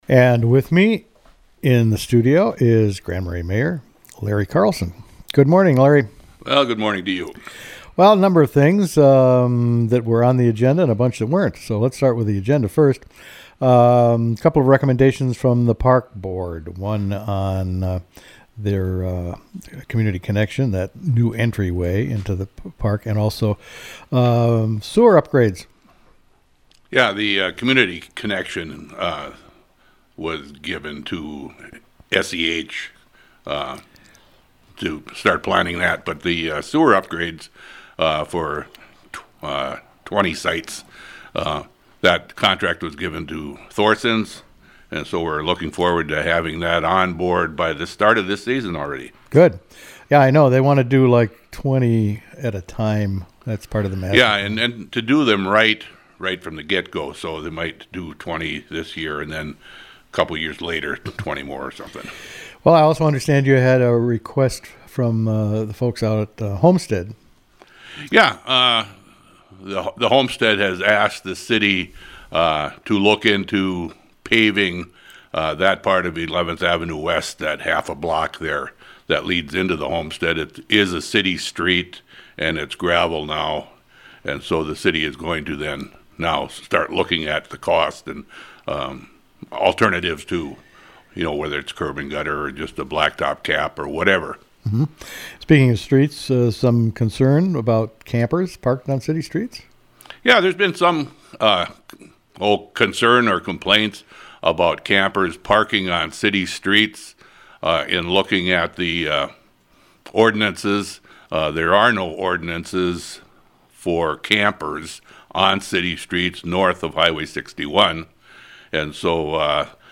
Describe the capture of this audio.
The interview was a part of Thursday’s AM Community Calendar program.